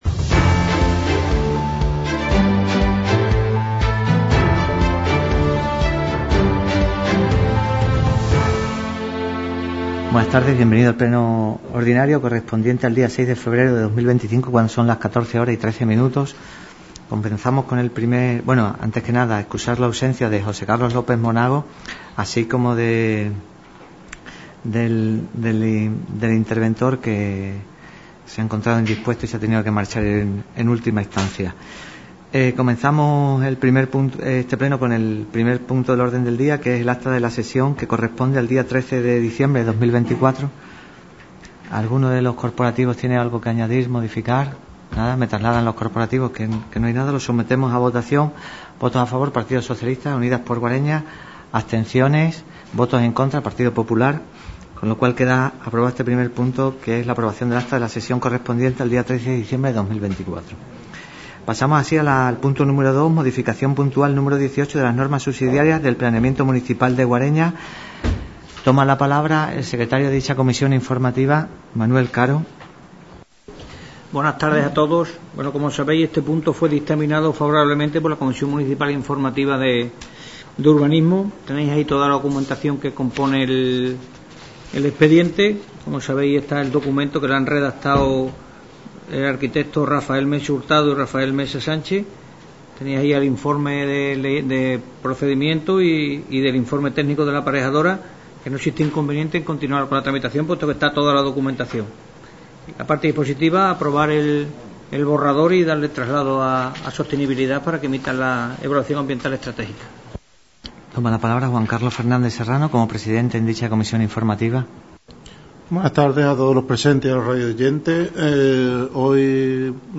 Sesión ORDINARIA de Pleno, 06 de Febrero de 2025 - radio Guareña
Sesión celebrada en el Ayuntamiento de Guareña.